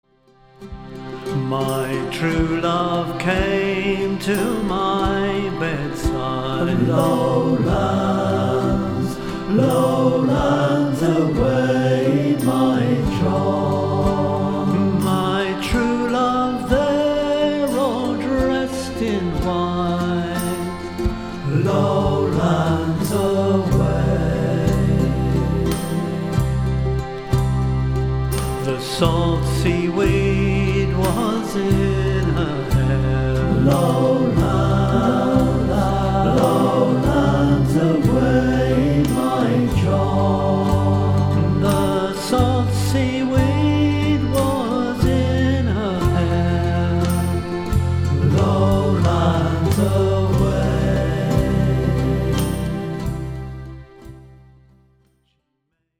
A traditional sea shanty.